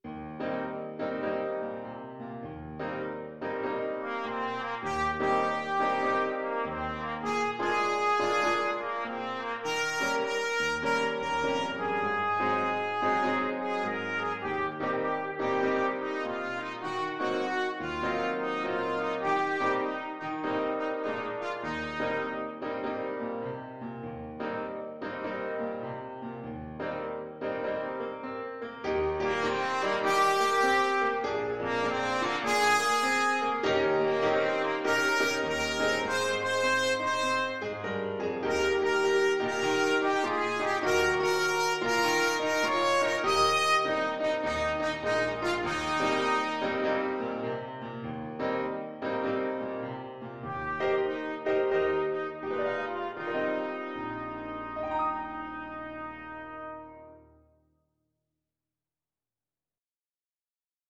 Trumpet
Moderate swing
Eb major (Sounding Pitch) F major (Trumpet in Bb) (View more Eb major Music for Trumpet )
4/4 (View more 4/4 Music)
Traditional (View more Traditional Trumpet Music)